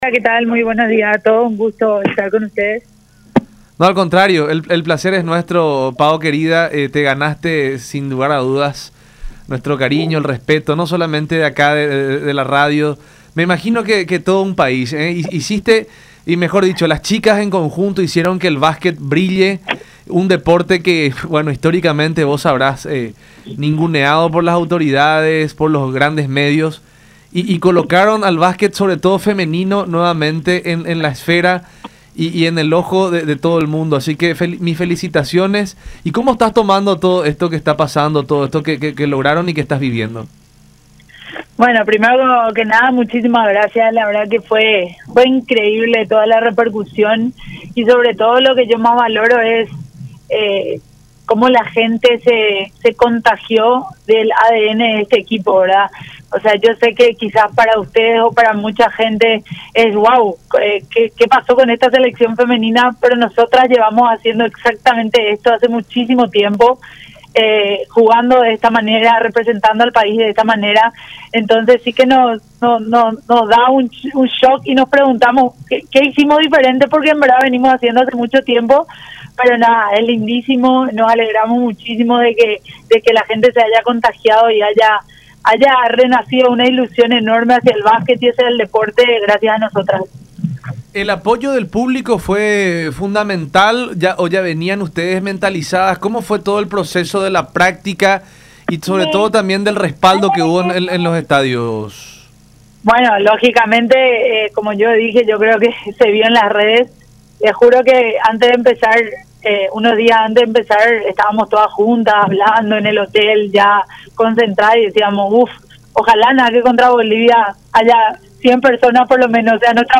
en diálogo con La Unión Hace La Fuerza por Unión TV y radio La Unión, agradeciendo a la afición paraguaya por el apoyo.